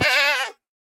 mob / goat / hurt3.ogg
hurt3.ogg